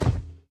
Minecraft Version Minecraft Version latest Latest Release | Latest Snapshot latest / assets / minecraft / sounds / mob / irongolem / walk2.ogg Compare With Compare With Latest Release | Latest Snapshot
walk2.ogg